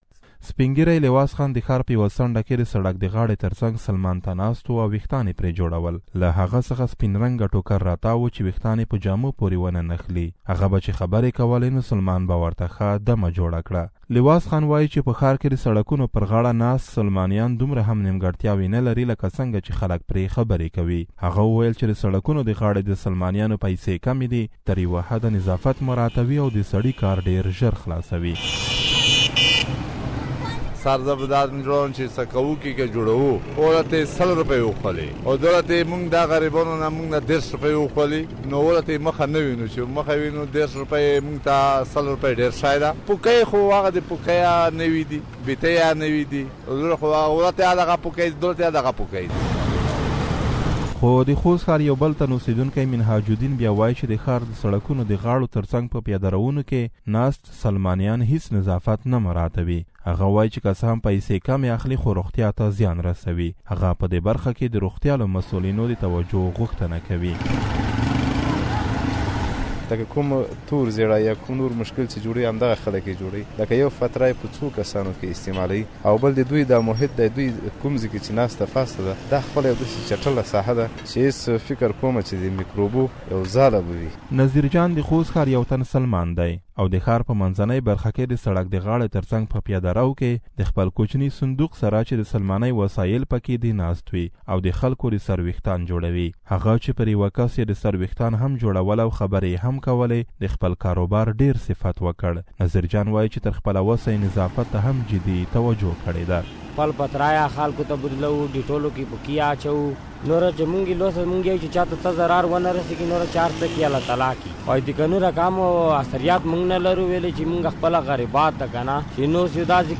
د خوست یو سلماني